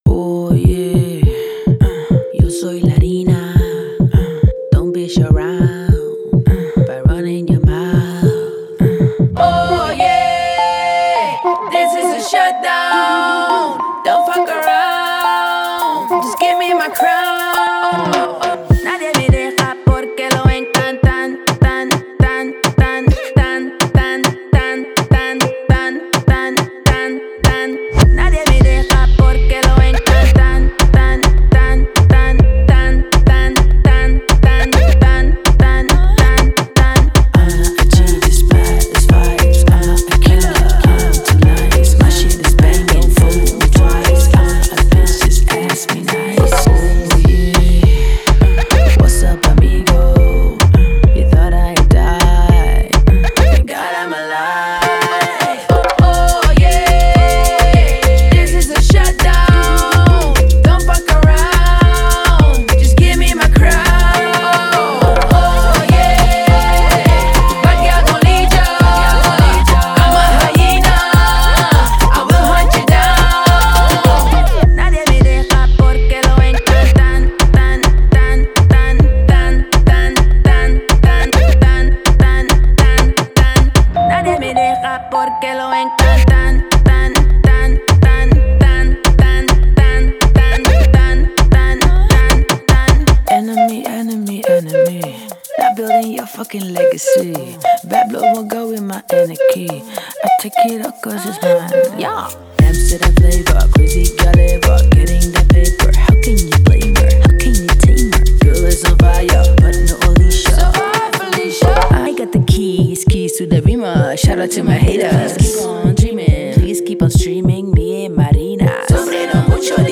энергичная электронная композиция